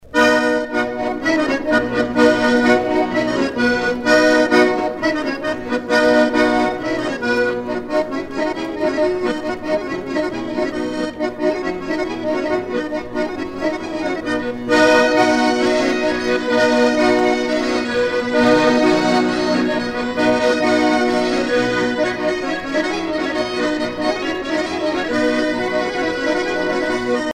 Polka piquée
danse : polka piquée
Pièce musicale éditée